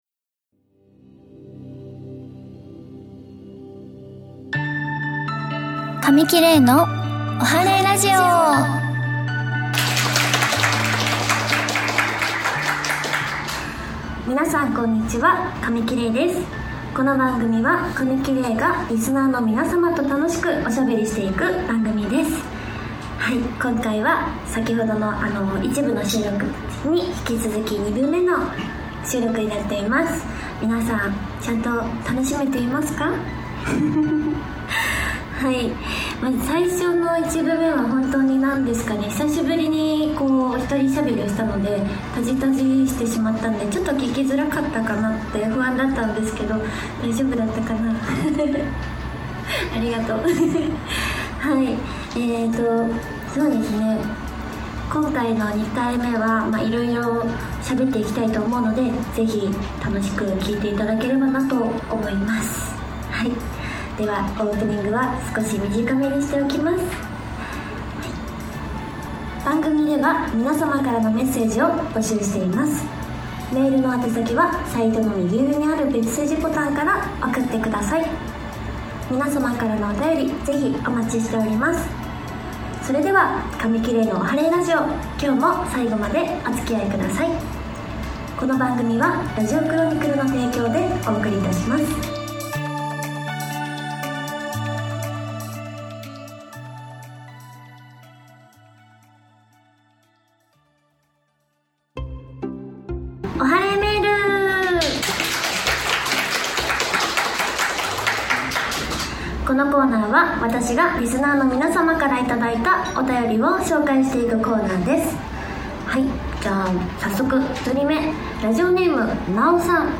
公開収録第2部もおかげさまで満員御礼となりました！